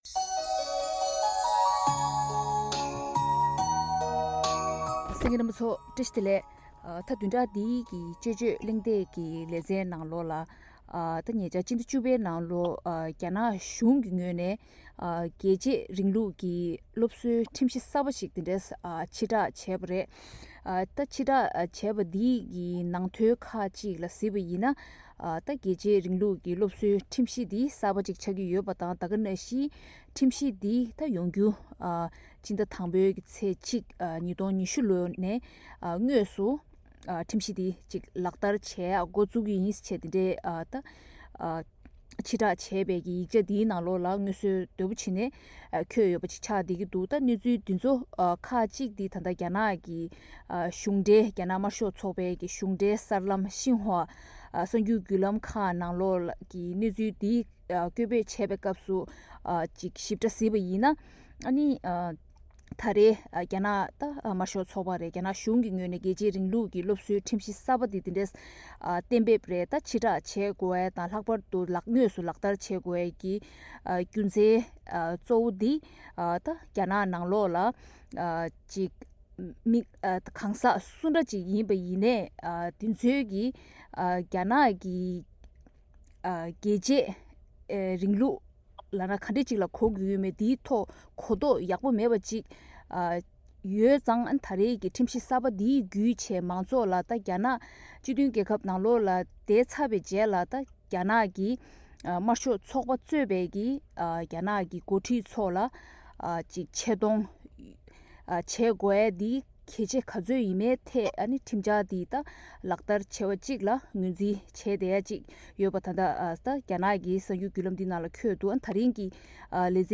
དཔྱད་གཞིའི་གླེང་མོལ